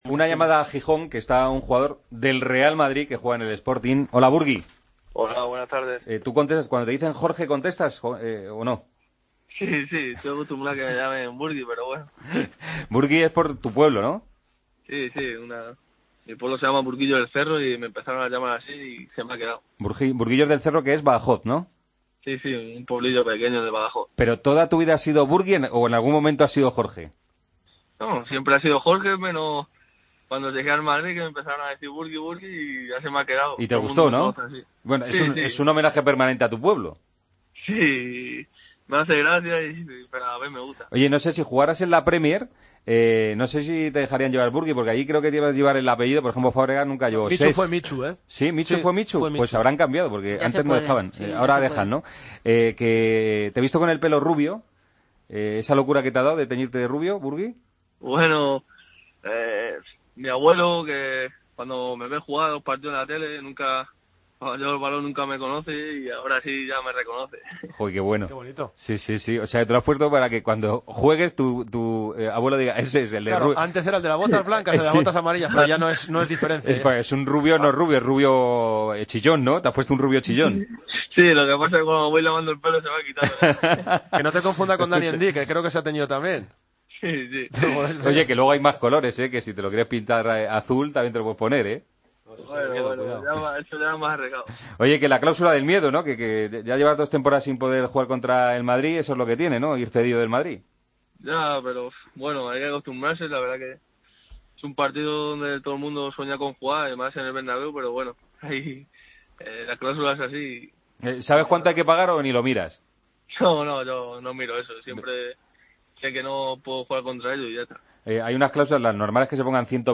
Hablamos con el jugador del Sporting de Gijón en la previa del encuentro ante el Real Madrid.